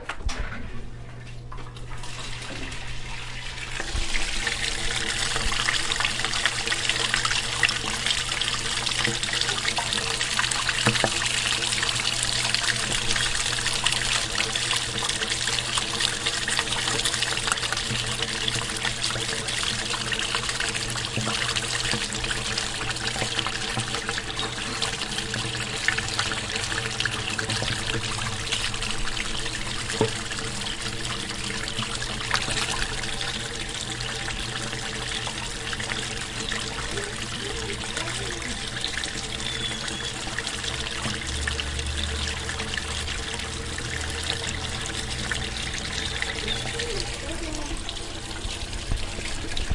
家用 " 厕所冲水
描述：在一个小型，空置的浴室中录制了Zoom H4N Pro Recorder。深沉，丰富，潮湿的声音
标签： 冲洗 管道 浴室 谐振
声道立体声